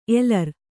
♪ elar